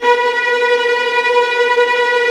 VIOLINT C#-R.wav